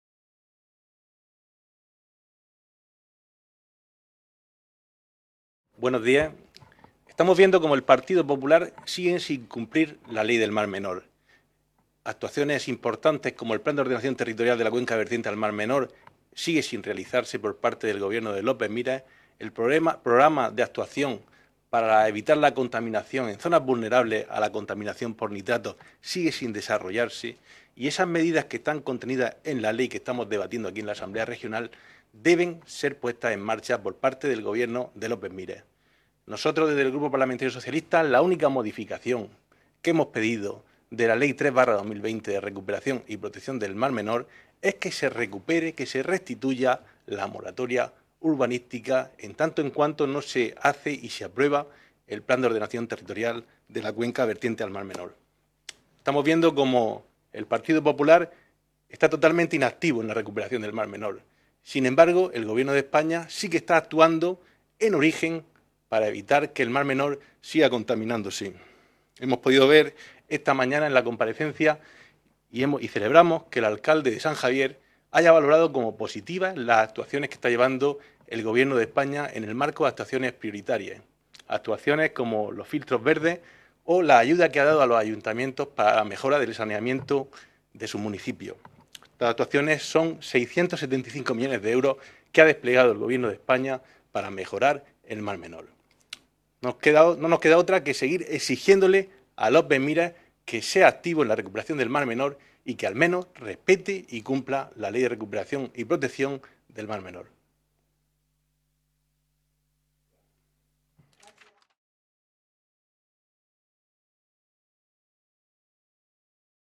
Ruedas de prensa tras la Comisión de Asuntos Generales e Institucionales, de la Unión Europea y Derechos Humanos